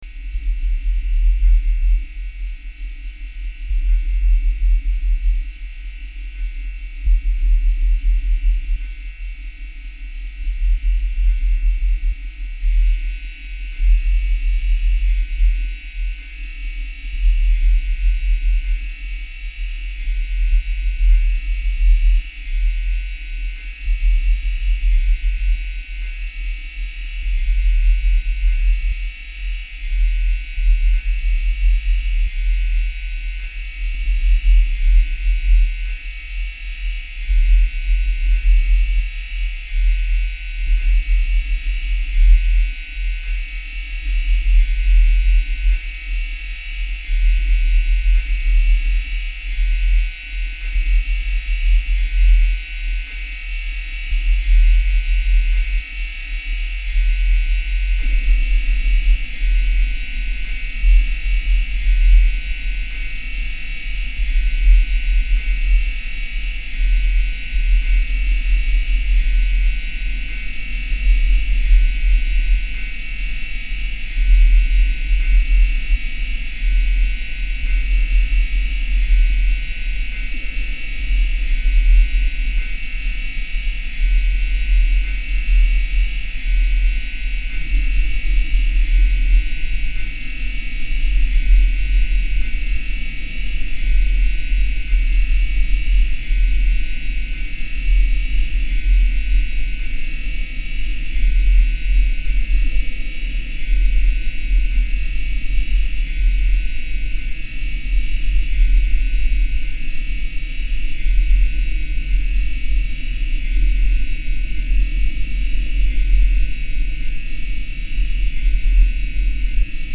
minimal electroacoustic soundscapes